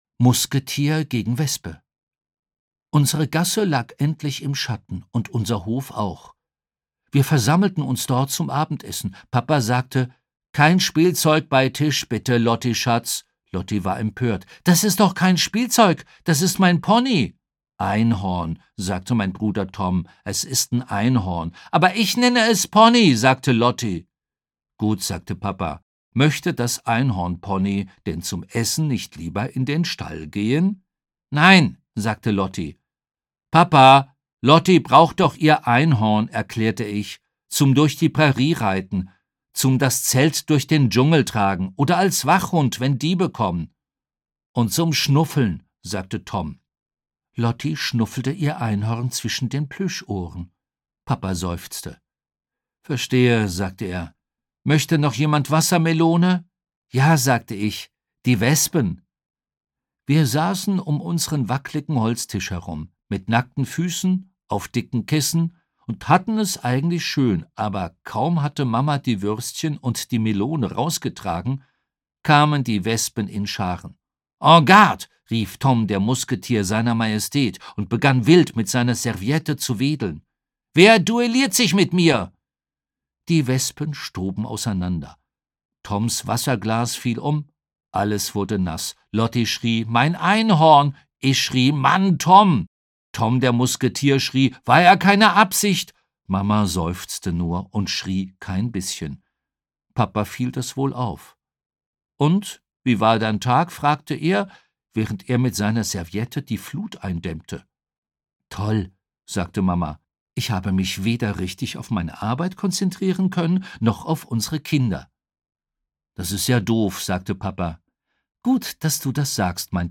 Die Lolli-Gäng sucht das Abenteuer Charlotte Inden (Autor) Rufus Beck (Sprecher) Audio-CD 2023 | 2.